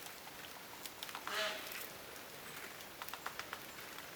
Ääniarvoitus.
Enpä tiennyt, että kauempaa kuultuna
niistämiseni voisi kuulostaa tuolta.